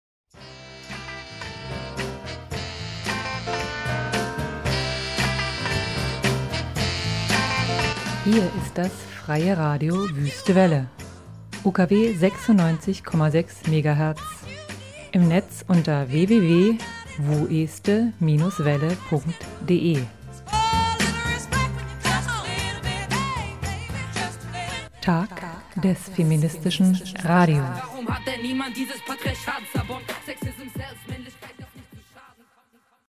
Der Jingle zum Sonderprogramm